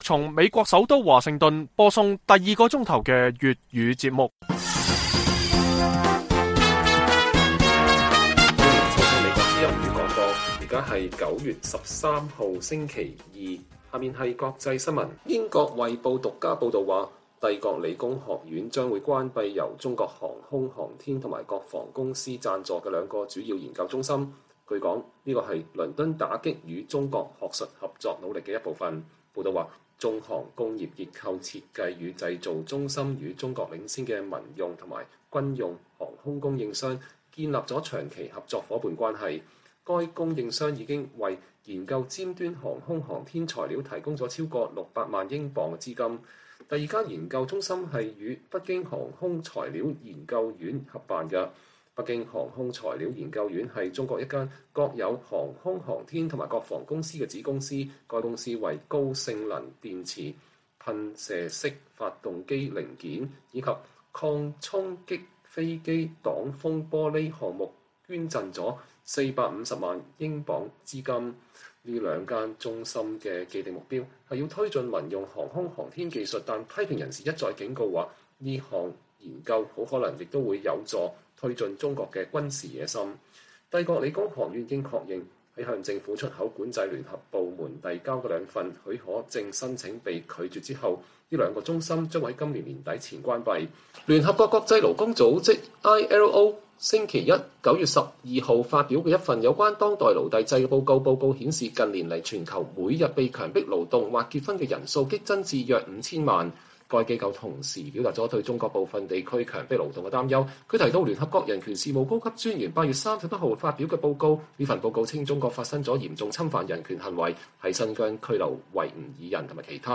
粵語新聞 晚上10-11點: 英國傳媒報道帝國理工學院將關閉與中國合作的國防研究機構